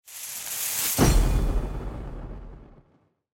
دانلود آهنگ آتش 16 از افکت صوتی طبیعت و محیط
دانلود صدای آتش 16 از ساعد نیوز با لینک مستقیم و کیفیت بالا
جلوه های صوتی